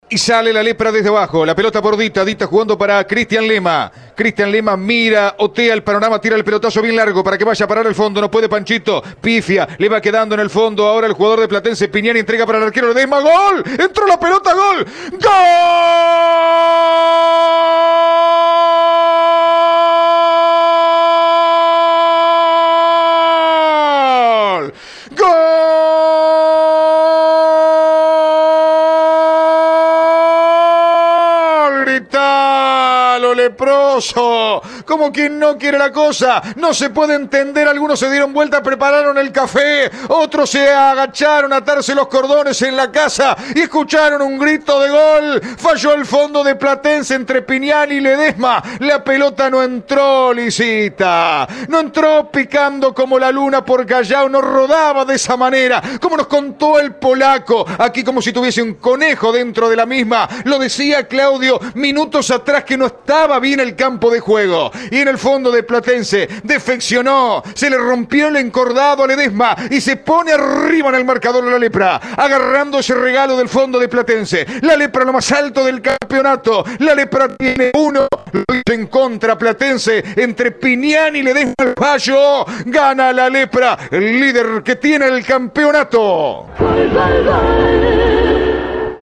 relataba por Cadena 3 Rosario el error no forzado entre Pignani y Ledesma que significó el gol rojinegro.